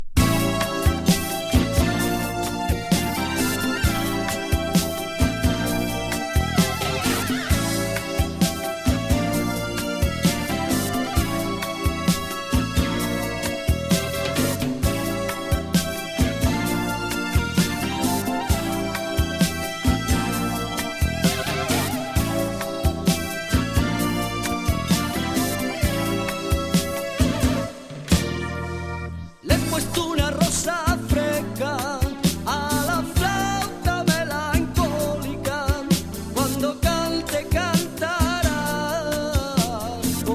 スペイン産フラメンコ。ムーディーなライトシンセ・プログレなA-2！ポップなA-3。